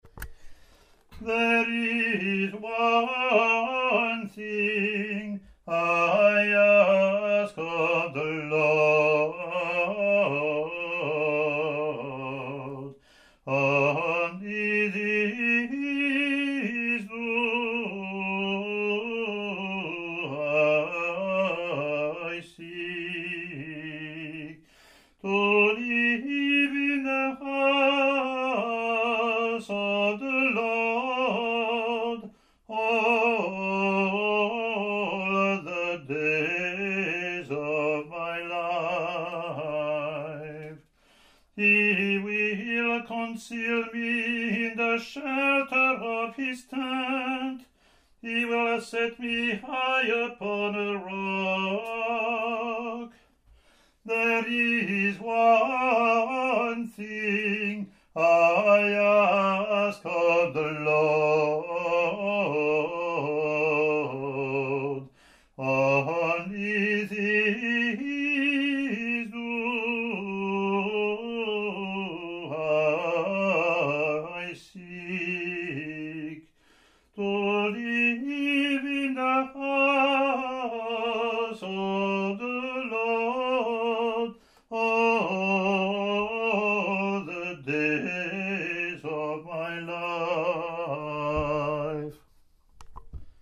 Click to hear Communion (
English antiphon – English verse Latin antiphon + verses)